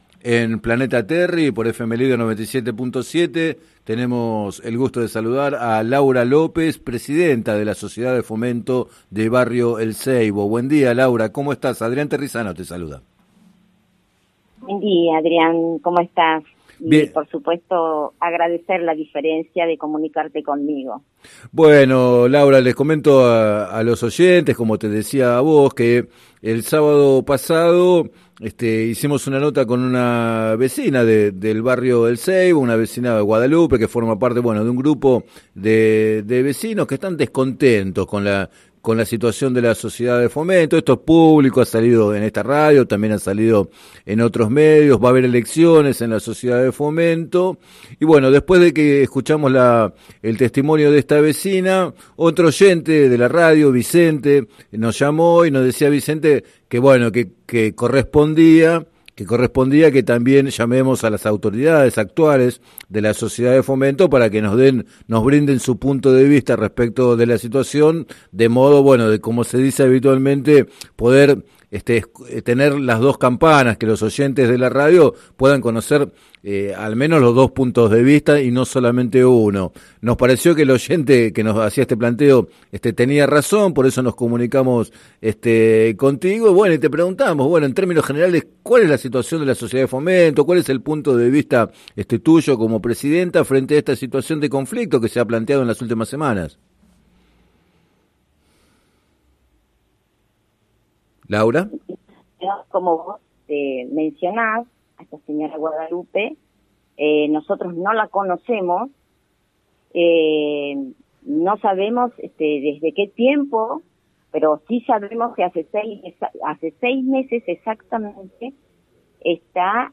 En declaraciones al programa “Planeta Terri” de FM Líder 97.7